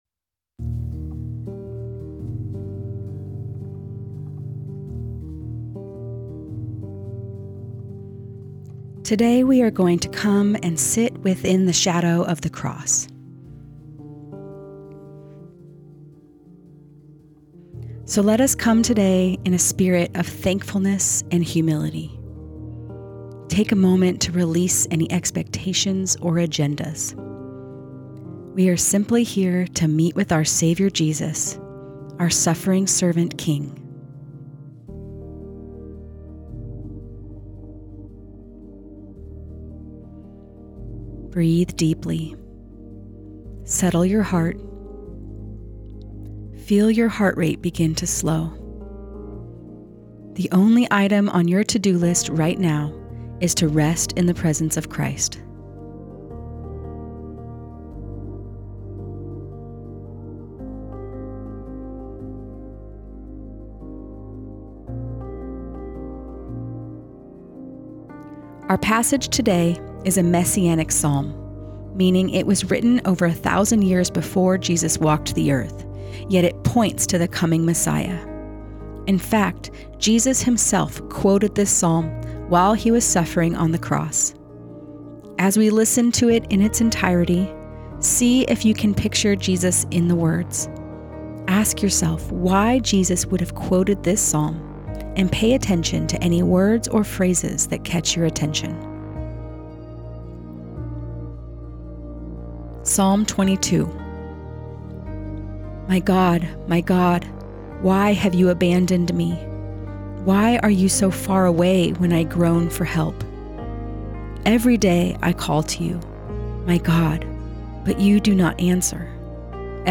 Listen and Pray through Psalm 22